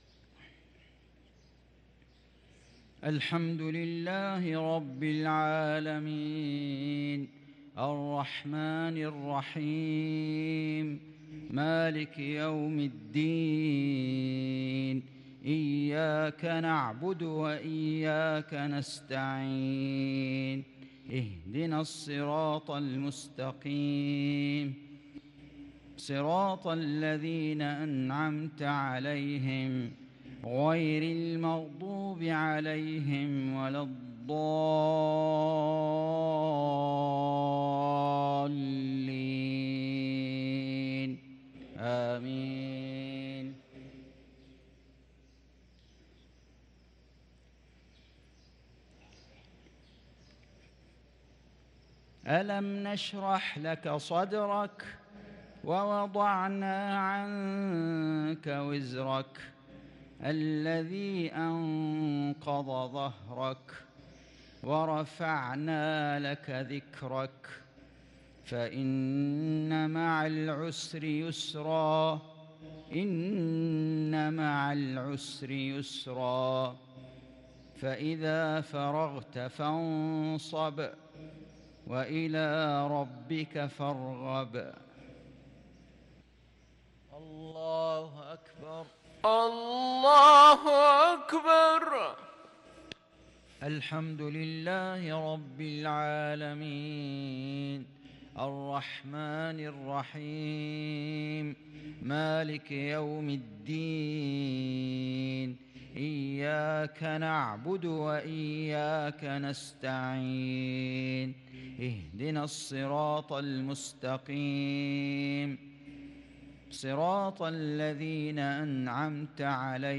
صلاة المغرب للقارئ فيصل غزاوي 27 ربيع الأول 1444 هـ
تِلَاوَات الْحَرَمَيْن .